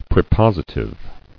[pre·pos·i·tive]